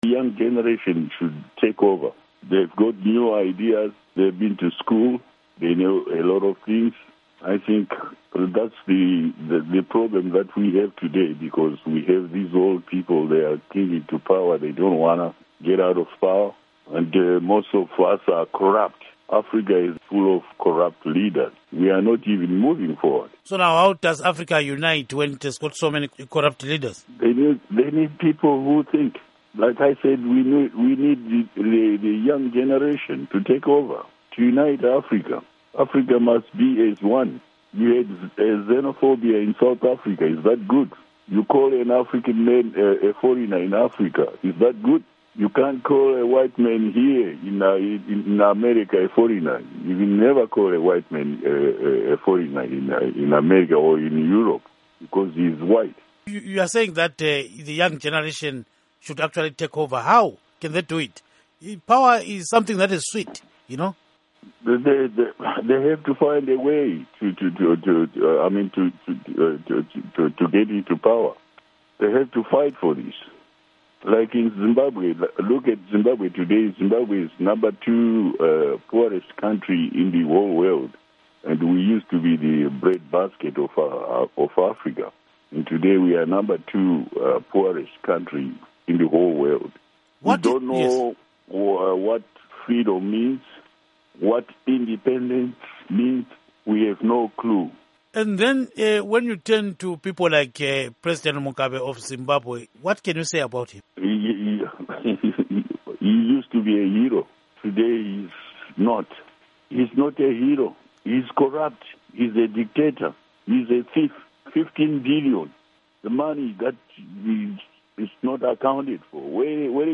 Interview With Nick Mangwana on Thomas Mapfumo's Revolution Remarks